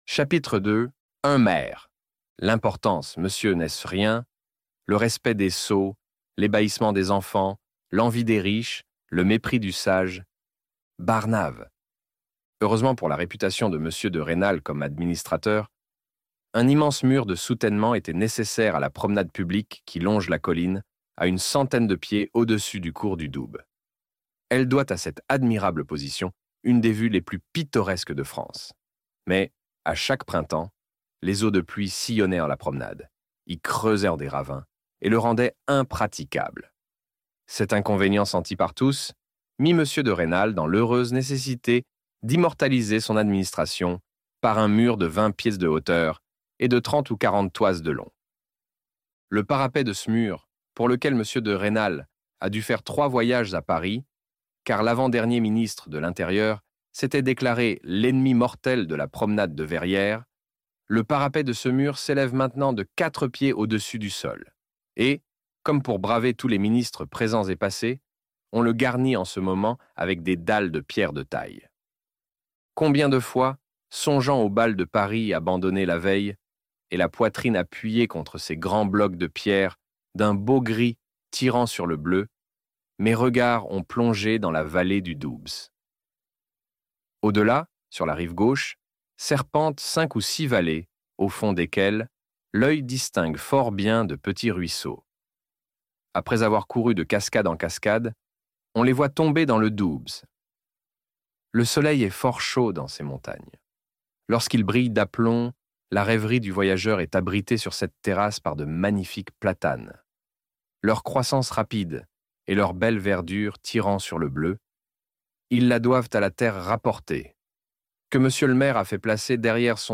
Le Rouge et le Noir - Livre Audio